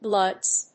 /blʌdz(米国英語)/